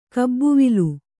♪ kabbuvilu